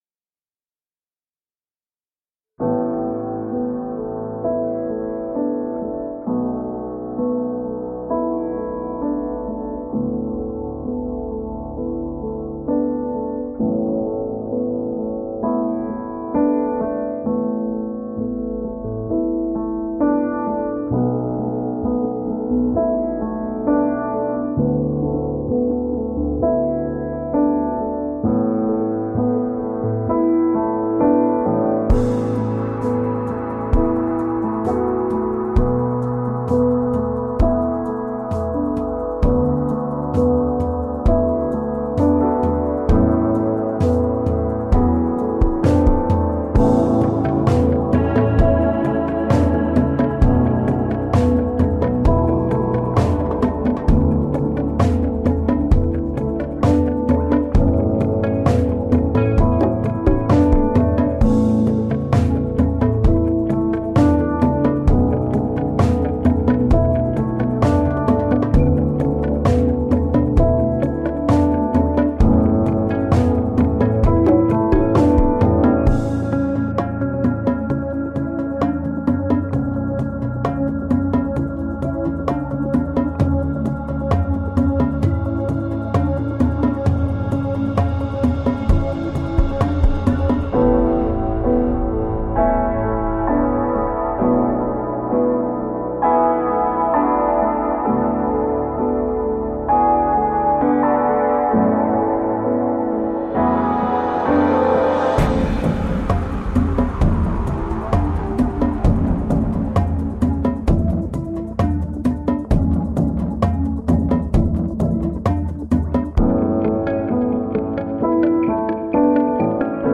Relaxed pop and reggae music made in italy.